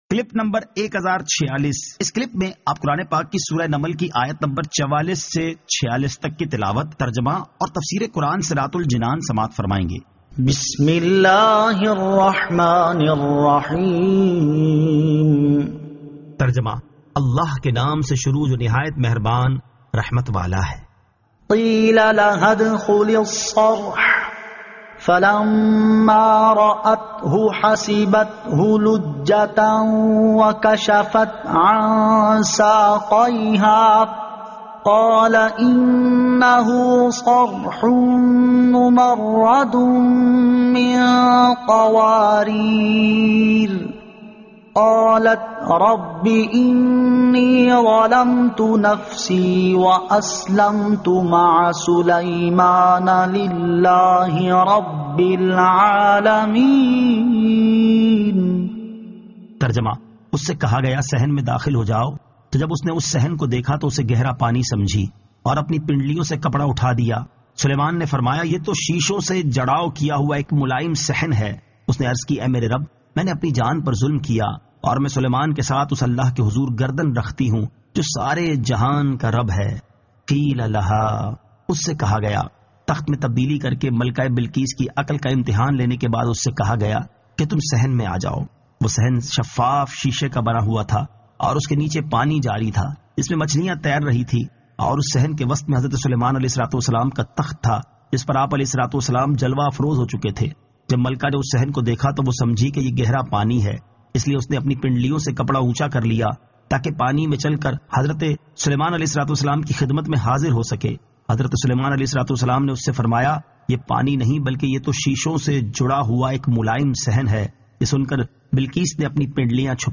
Surah An-Naml 44 To 46 Tilawat , Tarjama , Tafseer